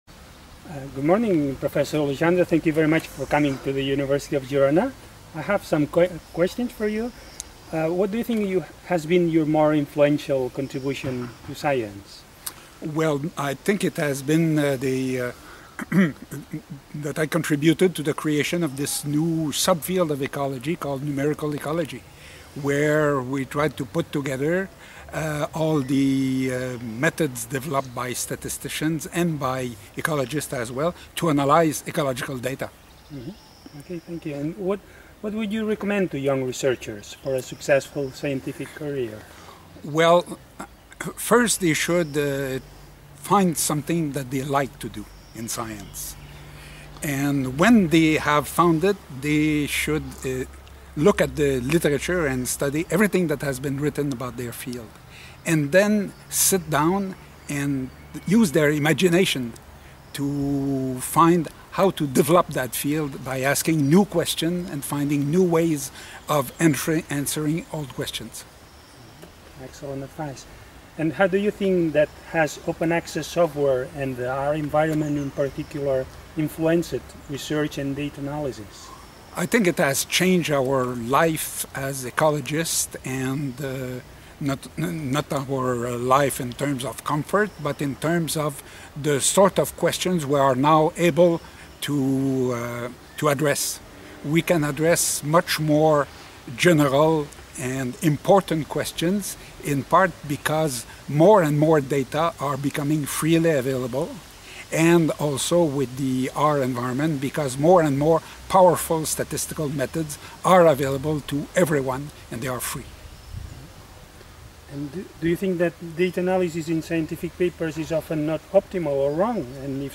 Entrevista a Pierre Legendre, historiador del dret i psicoanalista francès